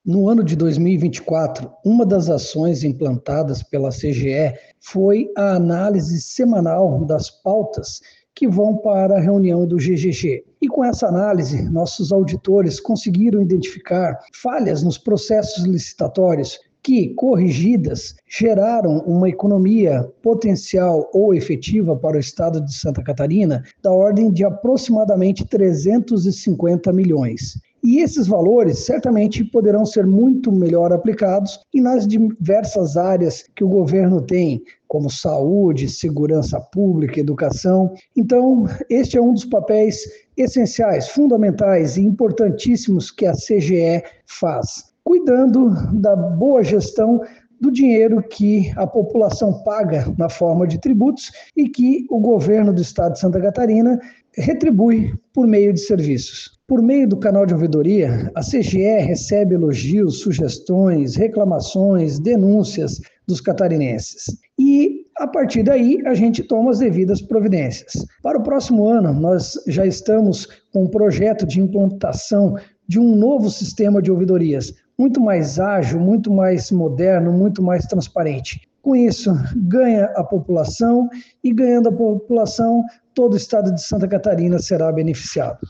O controlador-geral do Estado, Pedro Waltrick, comenta sobre as ações implantadas em 2024 e projeto novos desafios para 2025: